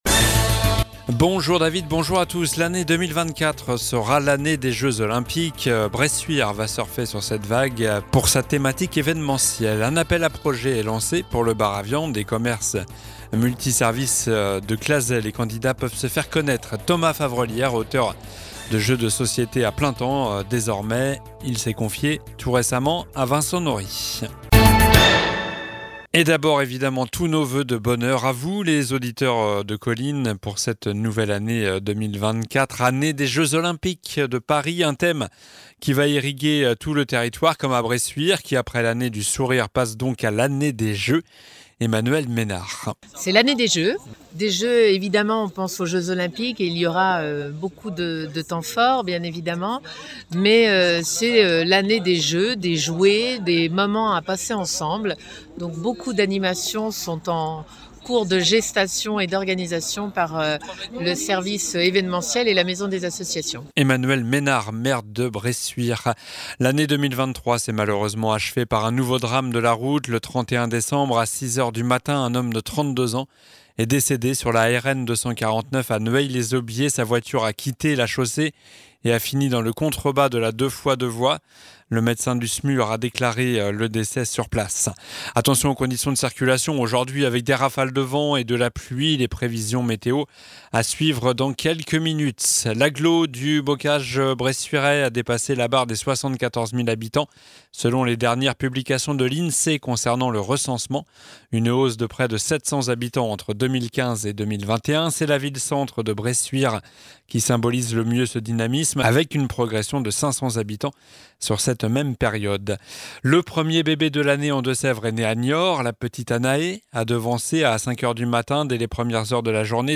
Journal du mardi 02 janvier (midi)